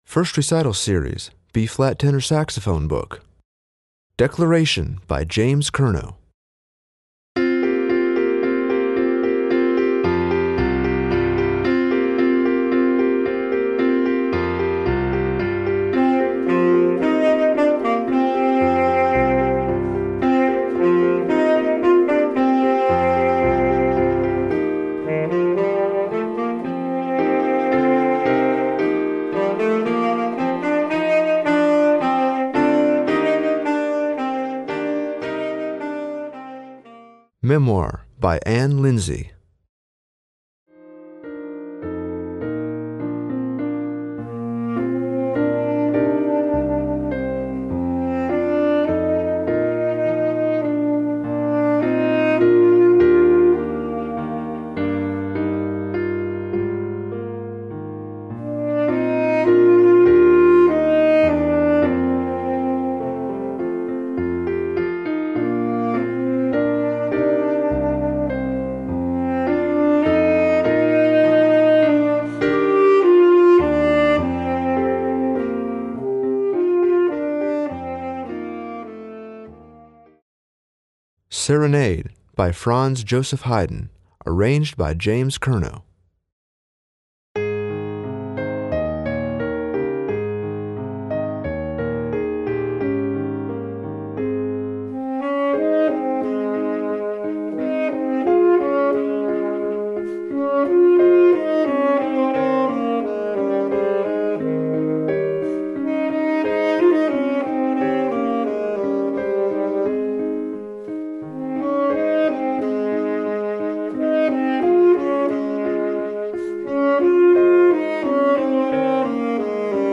Voicing: Piano Accompaniment